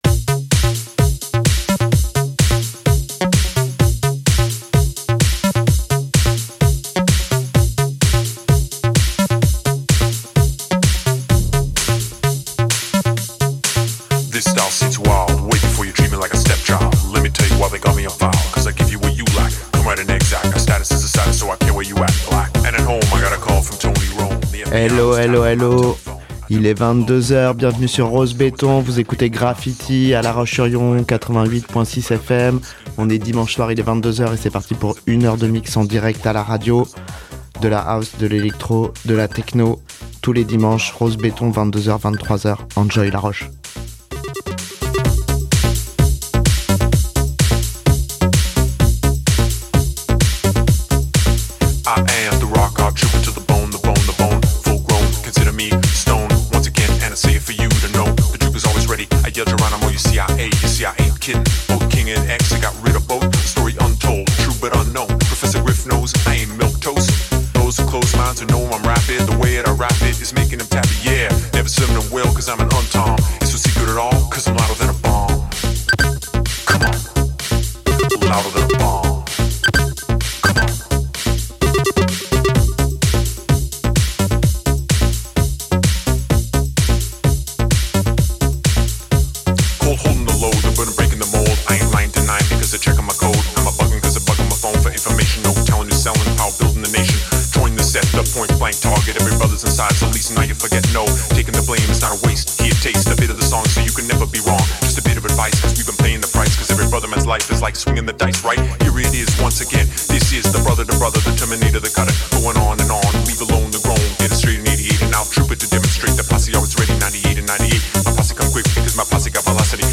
DJ sets en direct
ELECTRO - HOUSE - TECHNO